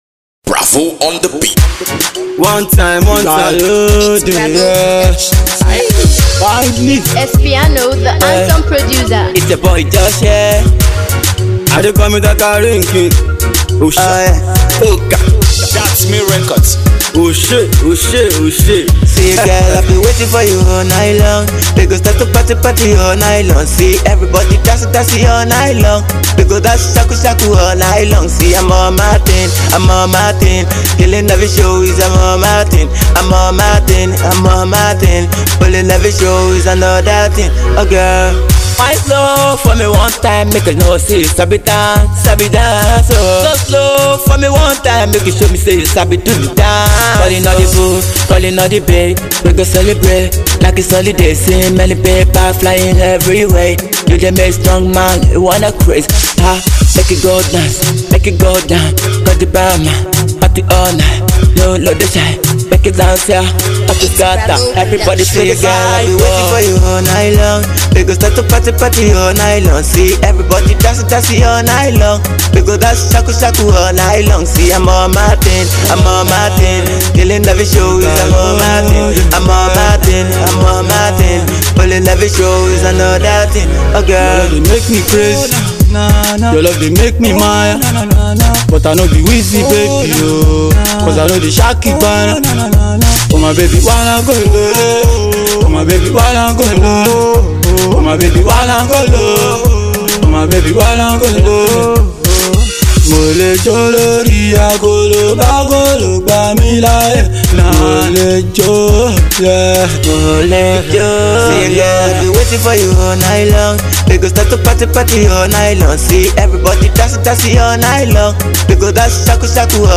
An Afro-Pop tune